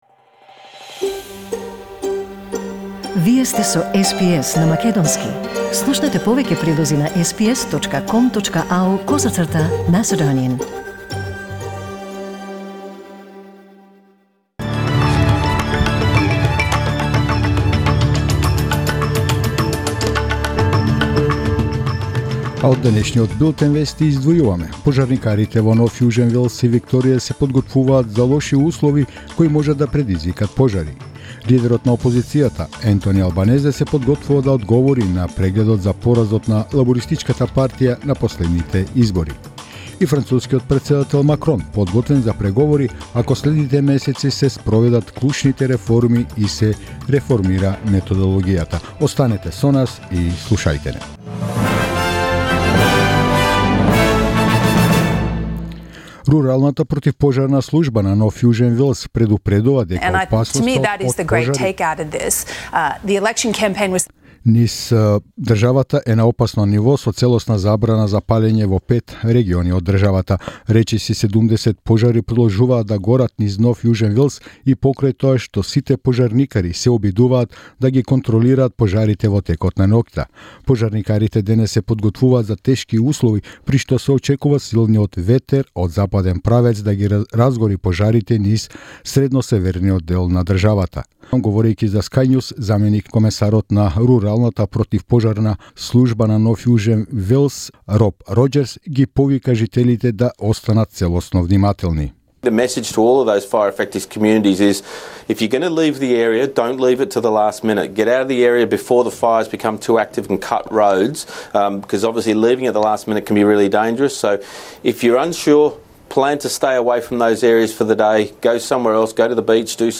SBS News in Macedonian 8th November 2019